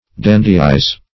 Dandyize \Dan"dy*ize\, v. t. & i. To make, or to act, like a dandy; to dandify.